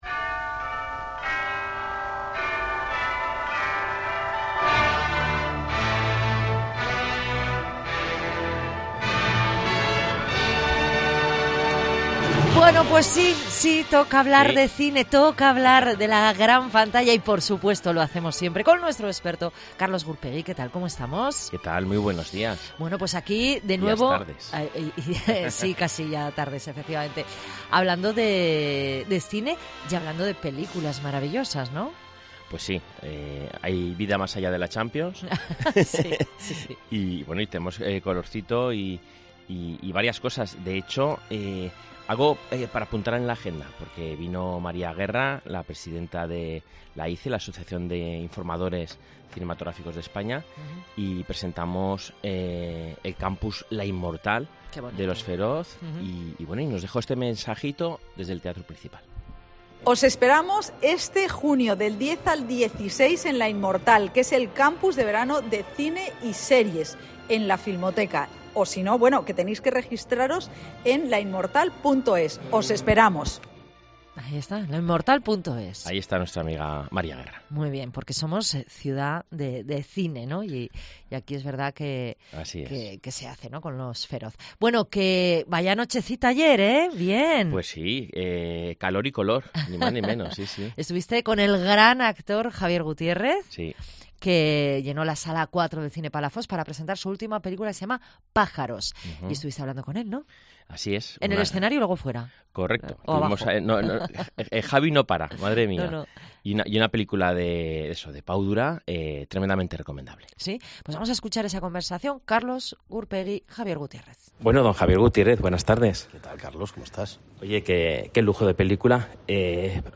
Entrevista al actor Javier Gutiérrez presentando 'Pájaros'